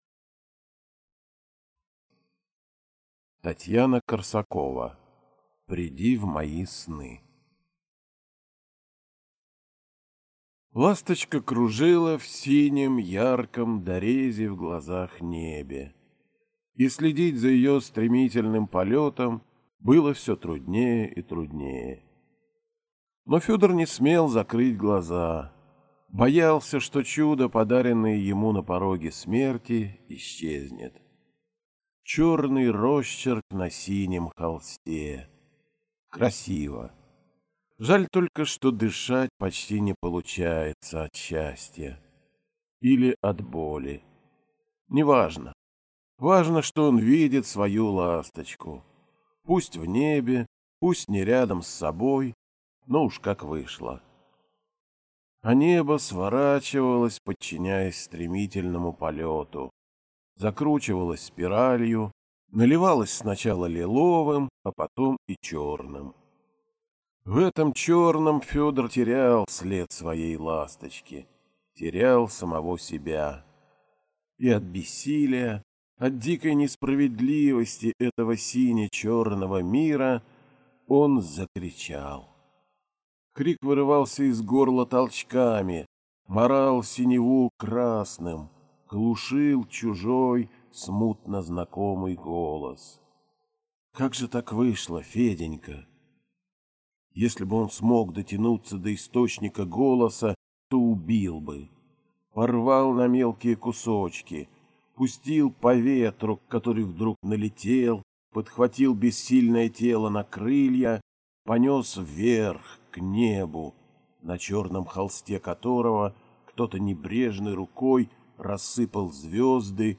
Аудиокнига Приди в мои сны - купить, скачать и слушать онлайн | КнигоПоиск